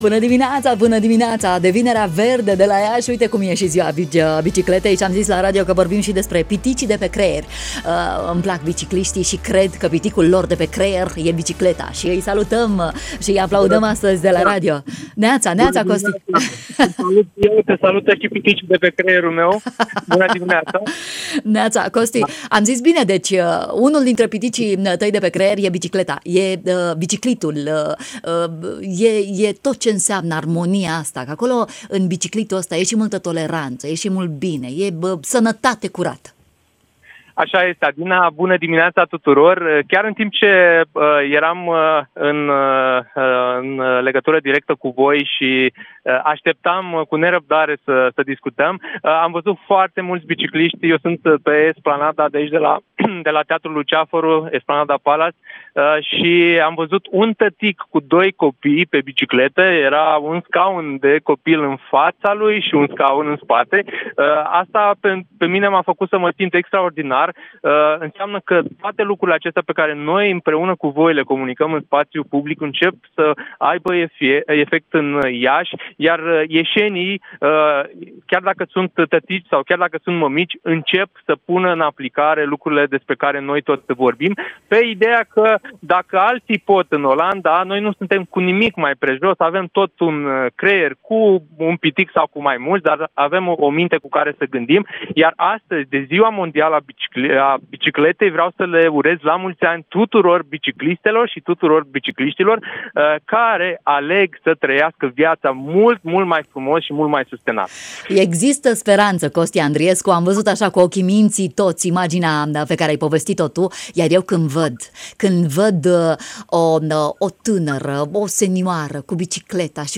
Astăzi, de „Ziua Mondială a Bicicletei” am vorbit la radio despre biciclete